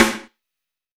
B.B SN 2.wav